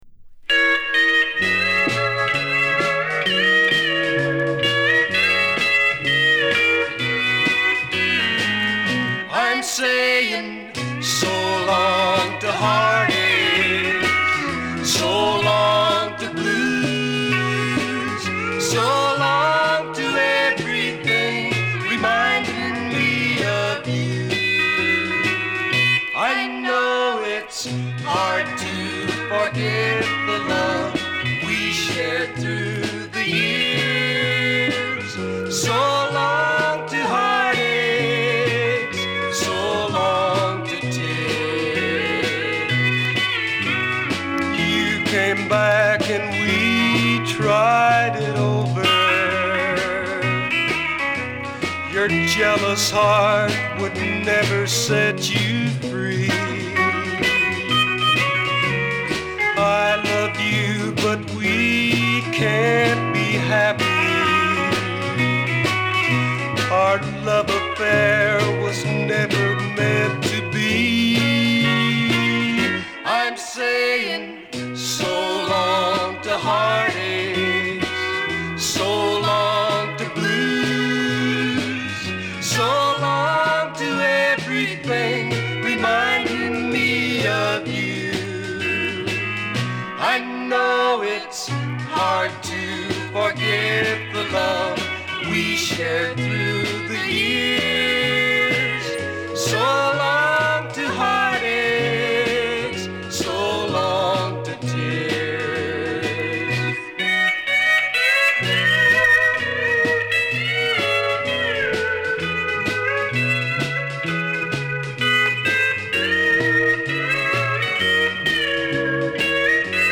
B面も歌心たっぷりの名演。